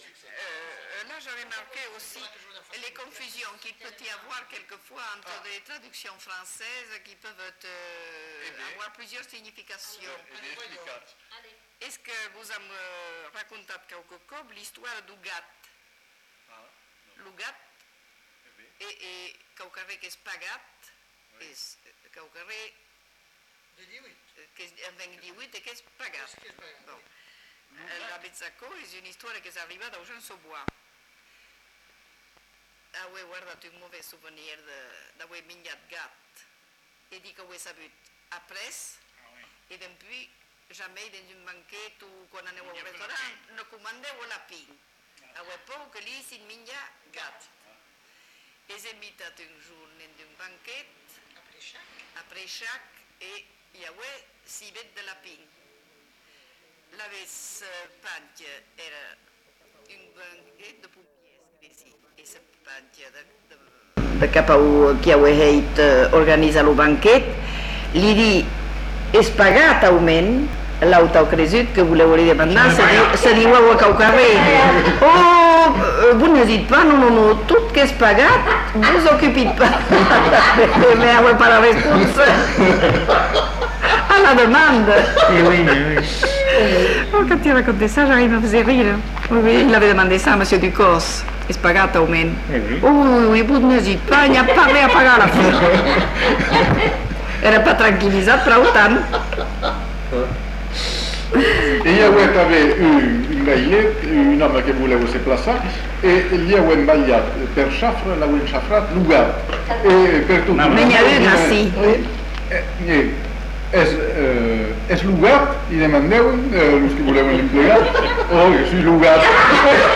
Aire culturelle : Bazadais
Lieu : Uzeste
Genre : conte-légende-récit
Effectif : 1
Type de voix : voix de femme
Production du son : parlé